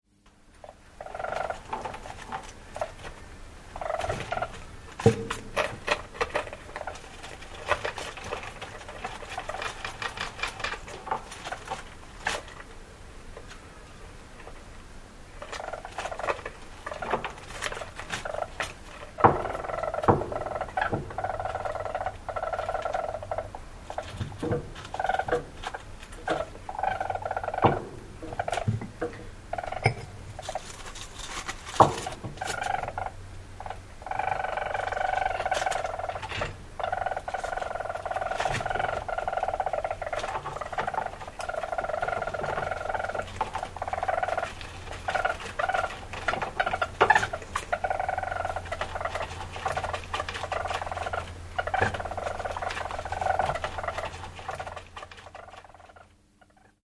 Звук игры и урчание ласки в клетке